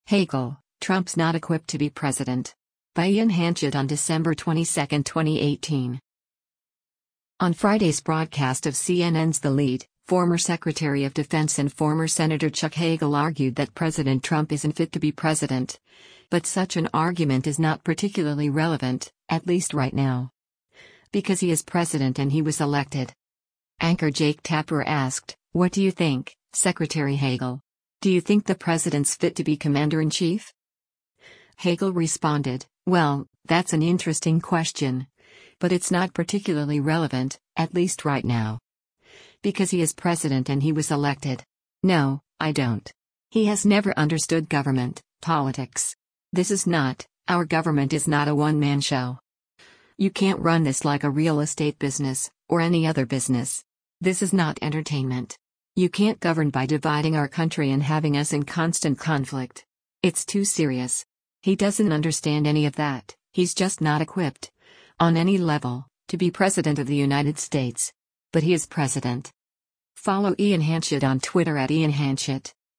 On Friday’s broadcast of CNN’s “The Lead,” former Secretary of Defense and former Senator Chuck Hagel argued that President Trump isn’t fit to be president, but such an argument is “not particularly relevant, at least right now. Because he is president and he was elected.”
Anchor Jake Tapper asked, “What do you think, Secretary Hagel? Do you think the president’s fit to be commander-in-chief?”